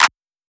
TM88 - CLAP (8).wav